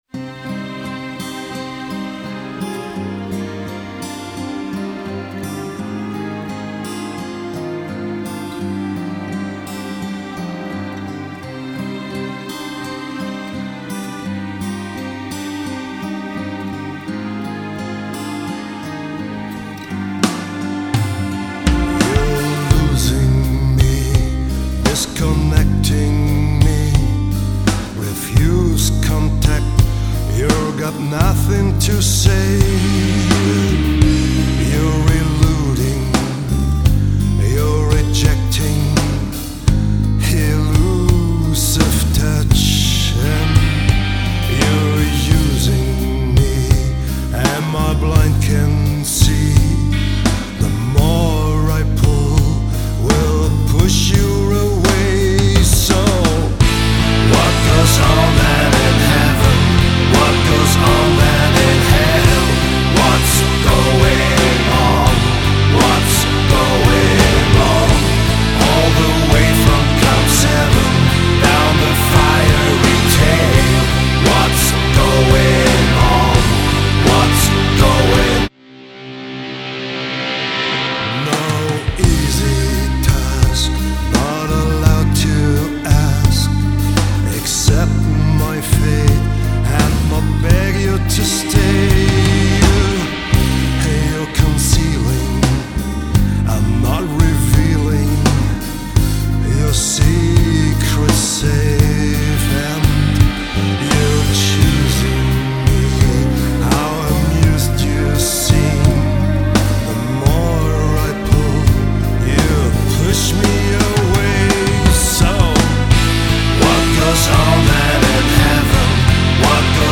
all Keyboards, vocals, string Arrangements
all female vocals
all drums
all basses
all guitars